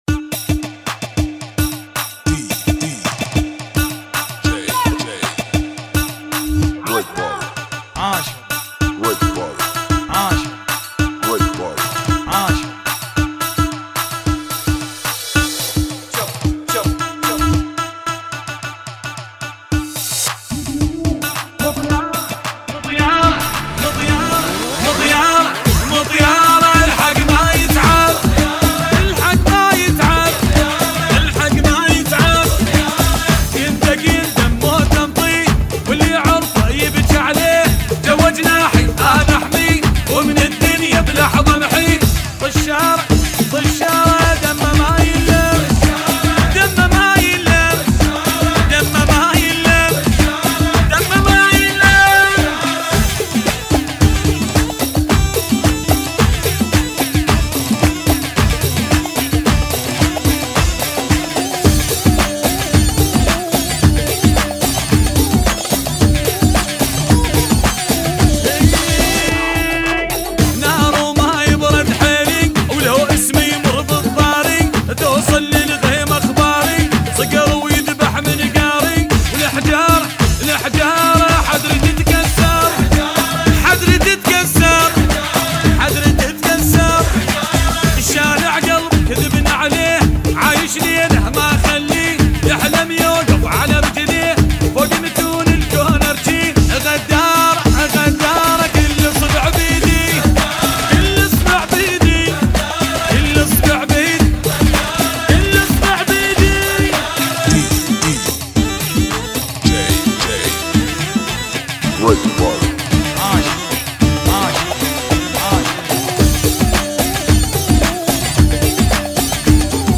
110 BPM